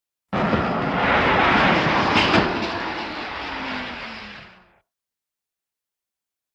Surface To Air Missile|Exterior
WEAPONS - VARIOUS SURFACE TO AIR MISSILE: EXT: Launch and rocket trailing, medium distance.